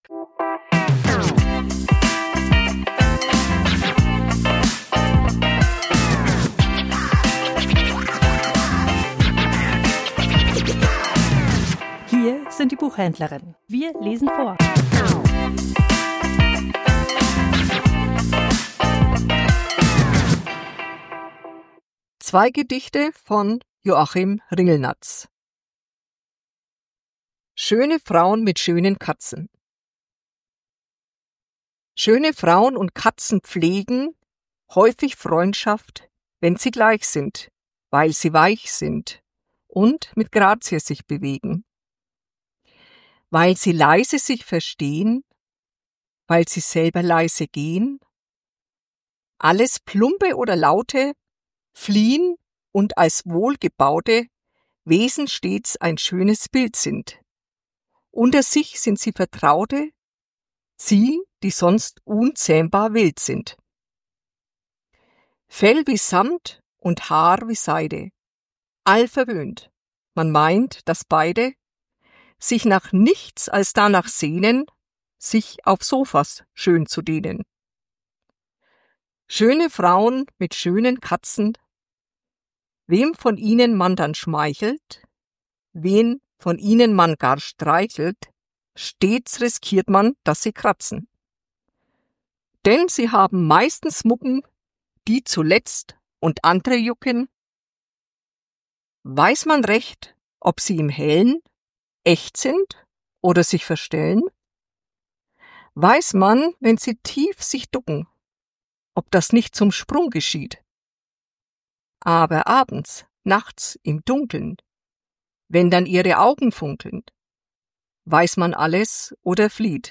Vorgelesen: 2 Gedichte von Joachim Ringelnatz